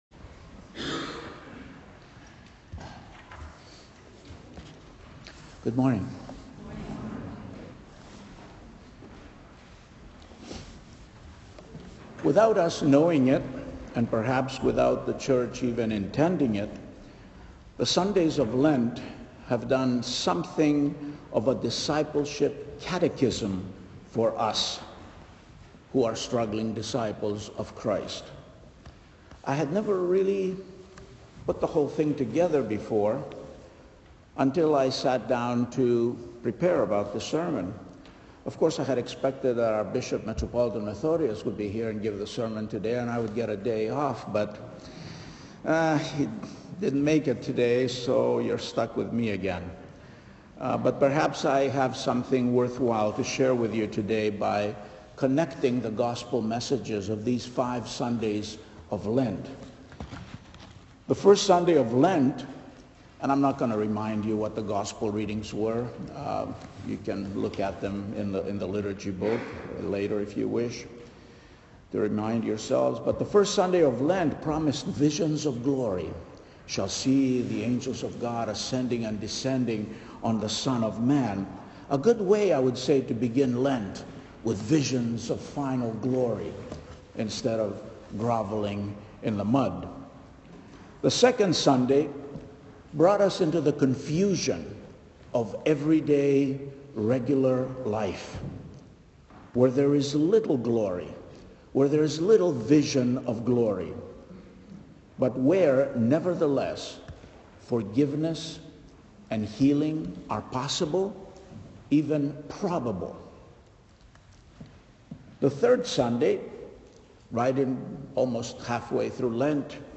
I had never realized this, until I sat down to work on this sermon.